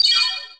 camera_shutter_crystal.wav